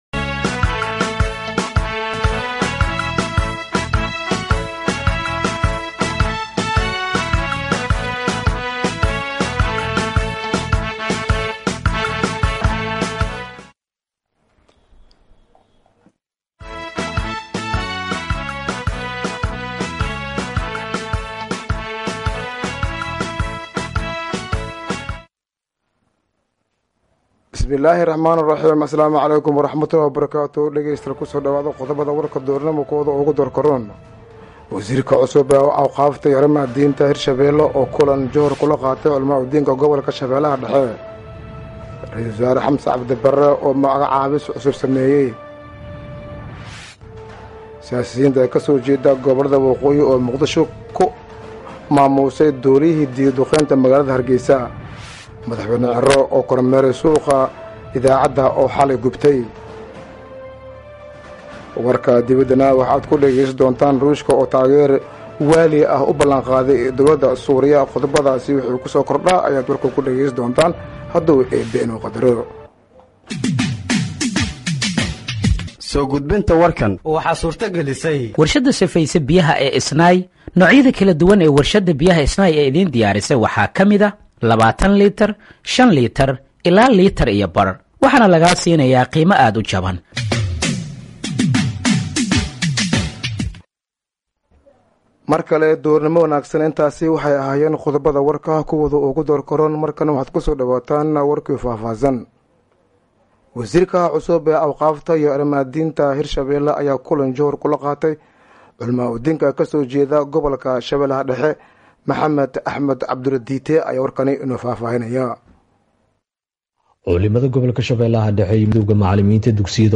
Halkaan Hoose ka Dhageeyso Warka Duhurnimo ee Radiojowhar